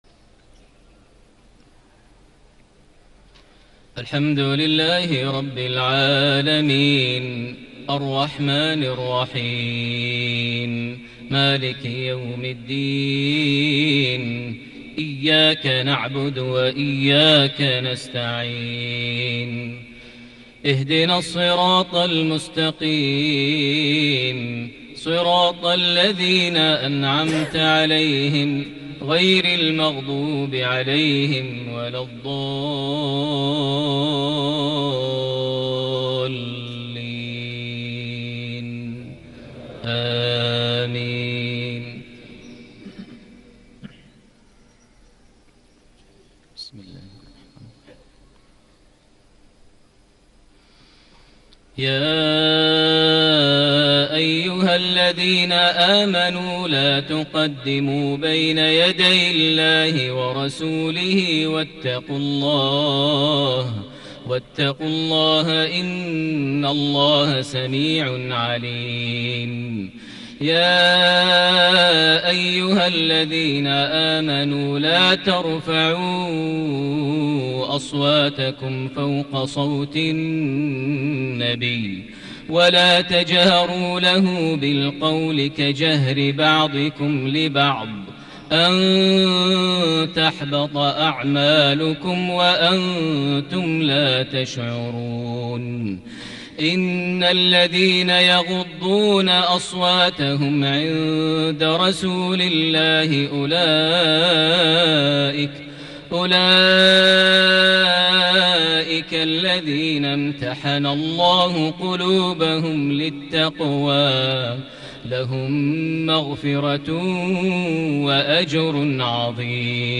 صلاة العشاء ١ جماد الآخر ١٤٤٠ هـ من سورة الحجرات ١-١٠ | lsha 6-2-2019 Surah Al-Hujraat > 1440 🕋 > الفروض - تلاوات الحرمين